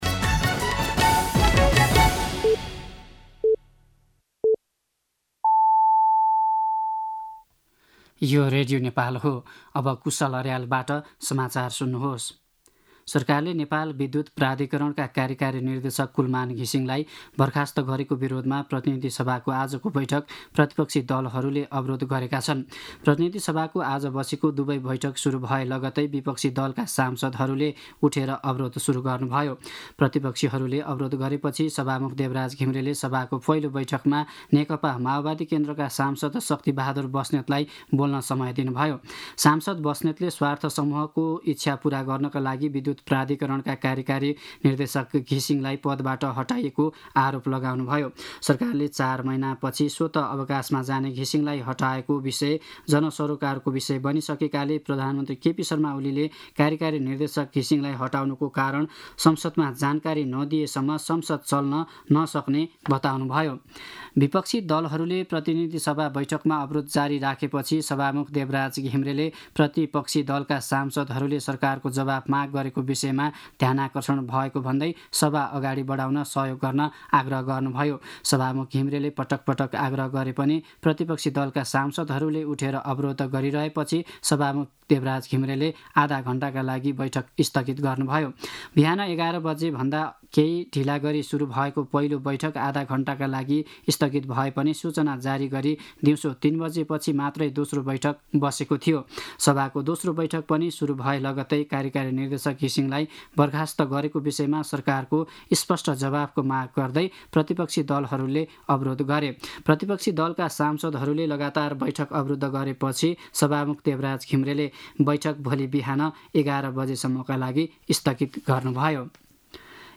दिउँसो ४ बजेको नेपाली समाचार : १३ चैत , २०८१
4-pm-News-12-13.mp3